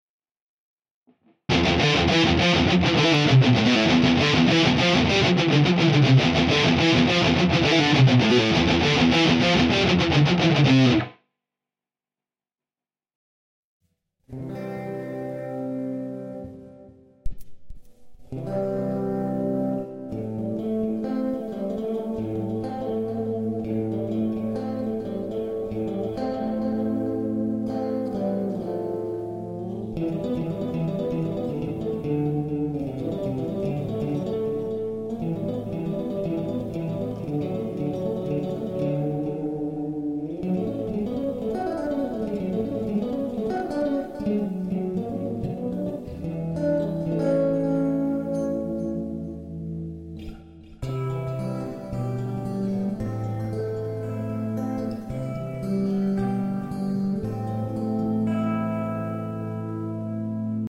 Вниз  Играем на гитаре
Правда плохая звуковуха на ноуте дает о себе знать.
Так что слегка фальшивлю.